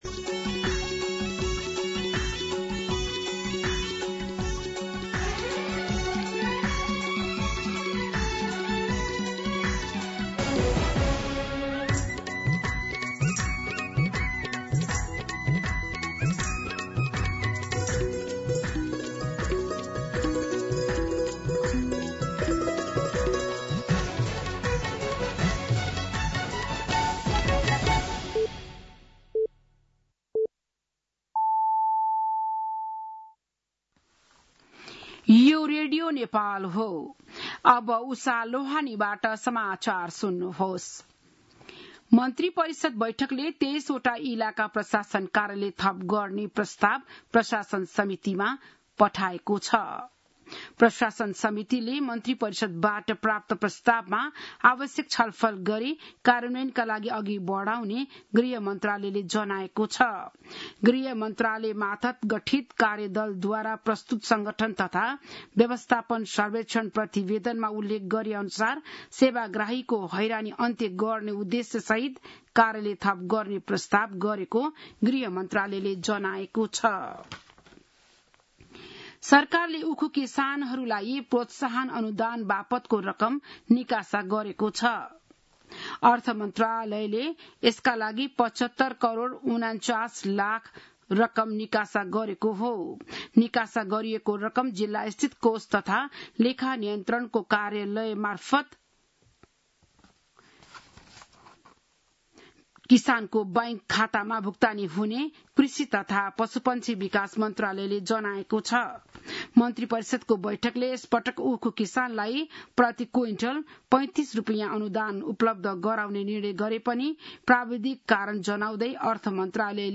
बिहान ११ बजेको नेपाली समाचार : ११ भदौ , २०८२